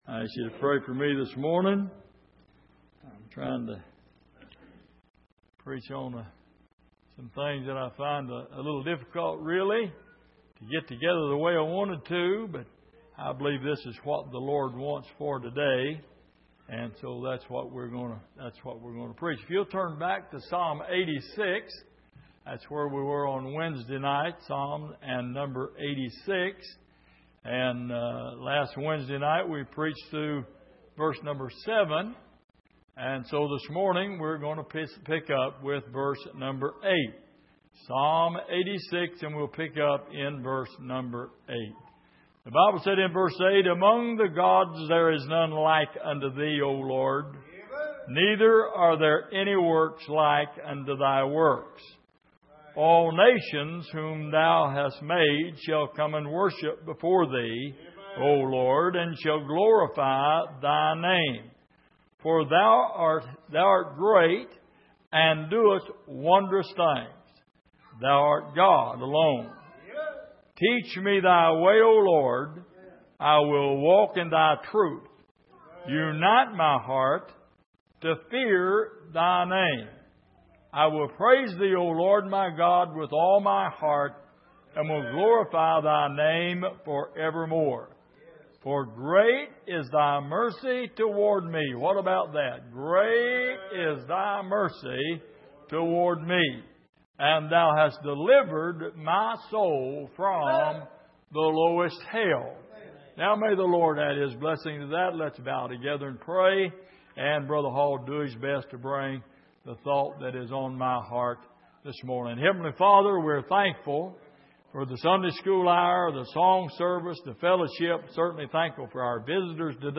Passage: Psalm 86:8-13 Service: Sunday Morning